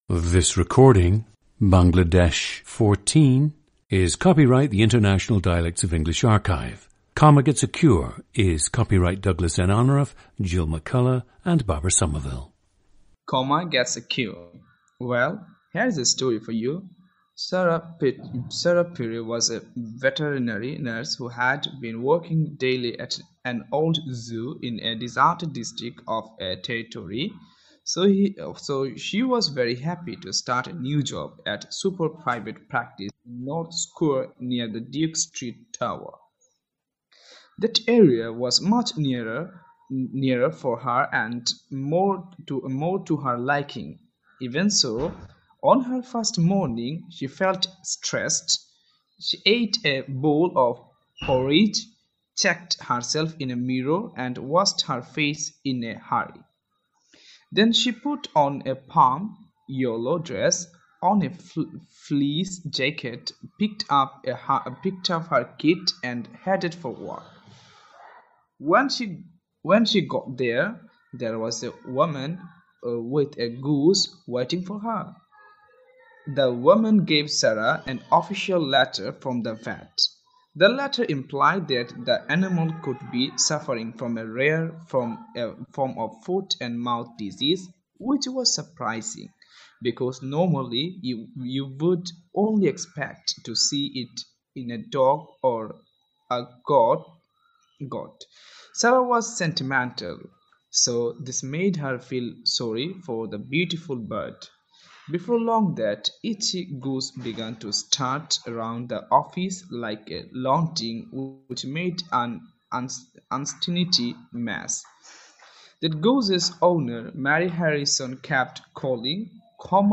Listen to Bangladesh 14, a 24-year-old man from Sylhet, Bangladesh.
GENDER: male
The main influence is his L1 language, Sylheti.
It seems that he is not yet able to distinguish between the vowels /æ/ and /e/ and uses them interchangeably.
This low speed is due to his fumbling on multisyllabic words and sometimes repeating words.
The subject does not have an affected English accent, which some Sylhetis acquire who are in close contact with their British relatives.